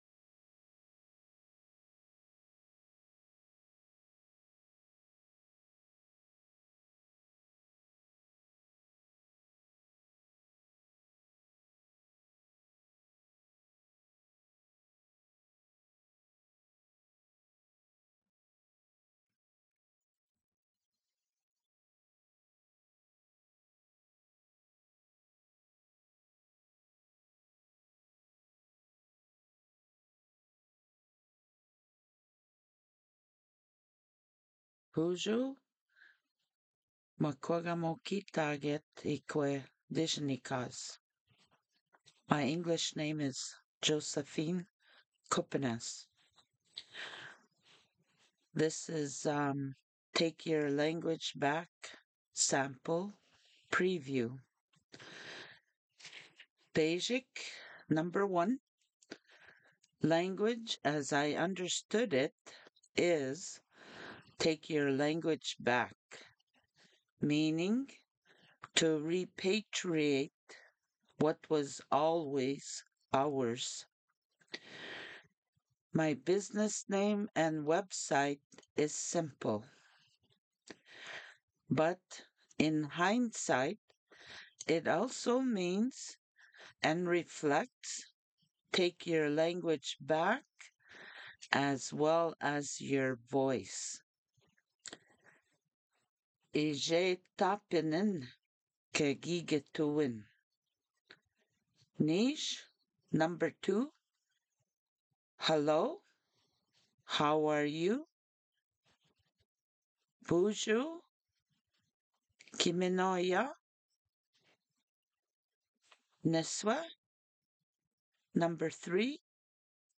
Learn from a fluent speaker
Listen to my podcast where you will listen and learn by audio only. You will learn the traditional way to speak the language.